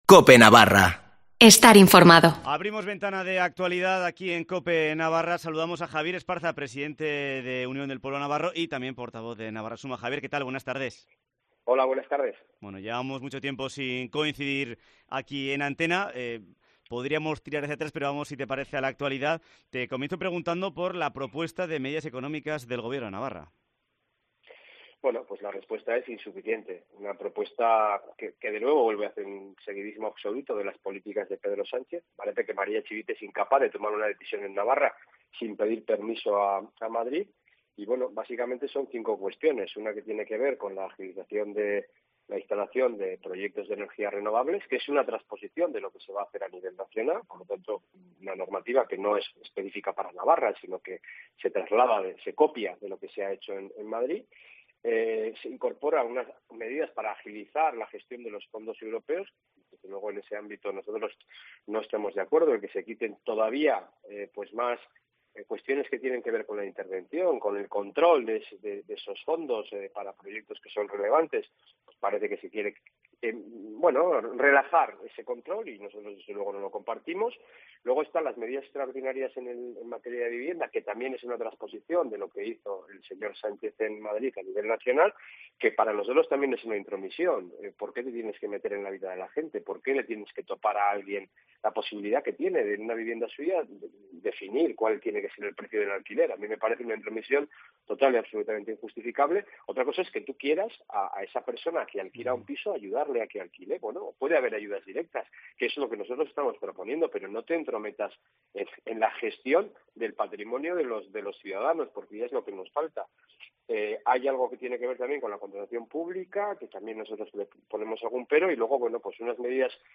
Entrevista con Javier Esparza, presidente de UPN y portavoz de Navarra Suma